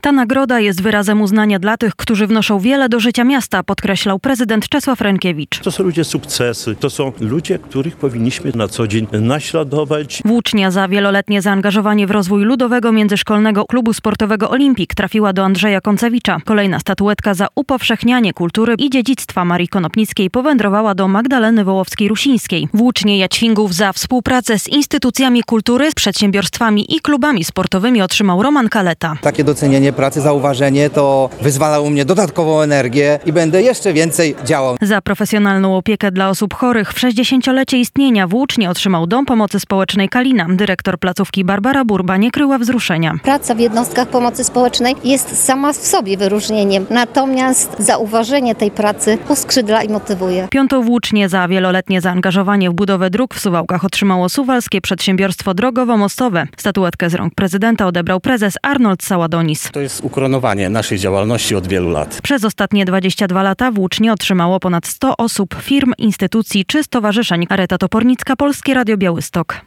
W sobotę (22.02) w hali Suwałki Arena Włócznie Jaćwingów trafiły do pięciu zasłużonych dla miasta osób i instytucji.
relacja
Ta nagroda jest wyrazem uznania dla tych, którzy wnoszą wiele do życia miasta - podkreślał prezydent Czesław Renkiewicz